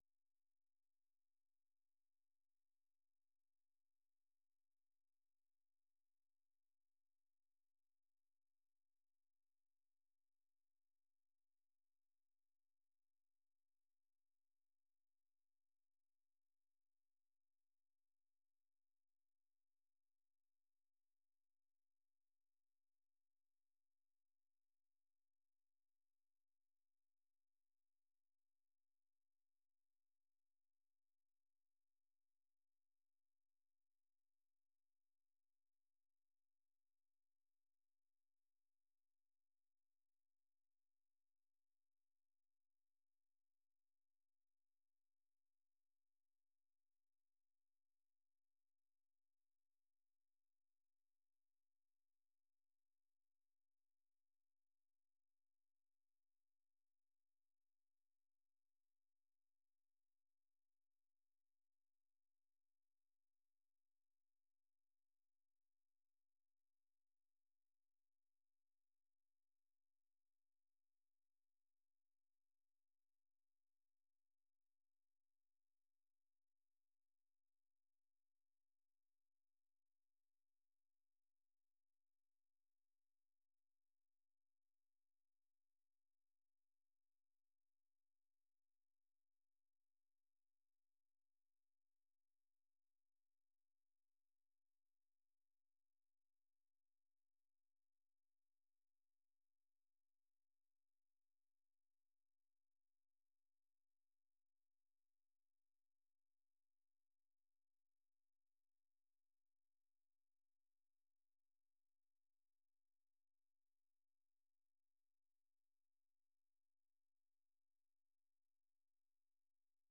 The URL has been copied to your clipboard 페이스북으로 공유하기 트위터로 공유하기 No media source currently available 0:00 0:59:58 0:00 생방송 여기는 워싱턴입니다 생방송 여기는 워싱턴입니다 공유 생방송 여기는 워싱턴입니다 share 세계 뉴스와 함께 미국의 모든 것을 소개하는 '생방송 여기는 워싱턴입니다', 저녁 방송입니다.